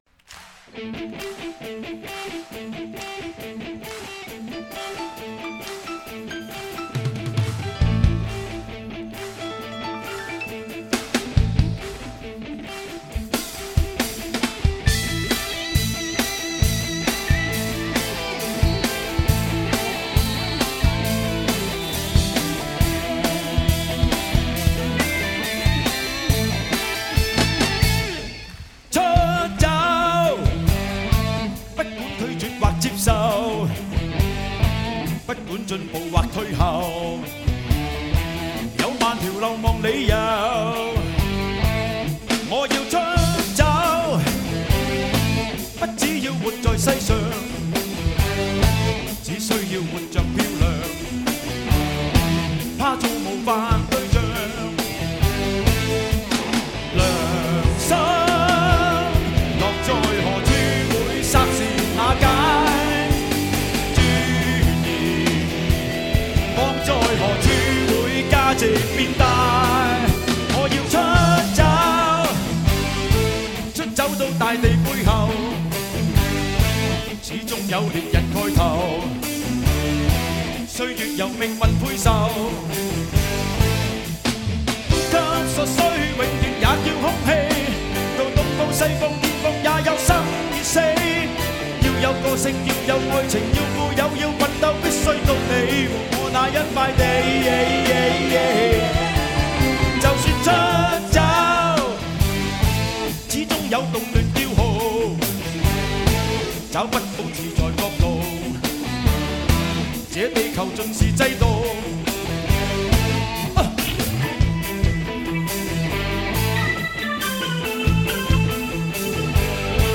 香港摇滚之父 怀旧粤语经典
高密度24BIT数码录音